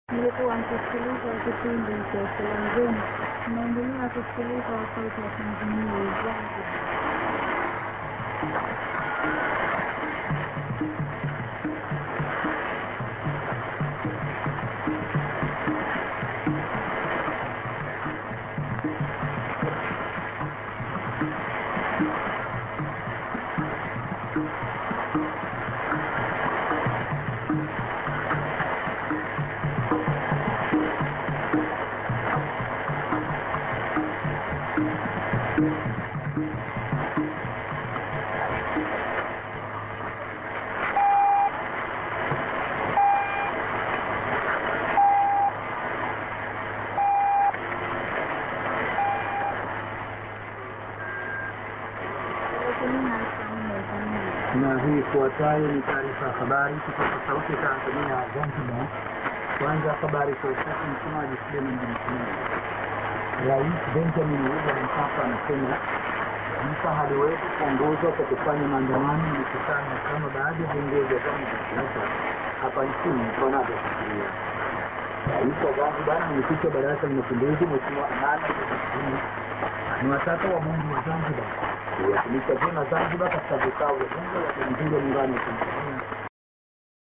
ANN(women)->durm->TS->ANN(wmoen+man:･･Zanziba･･)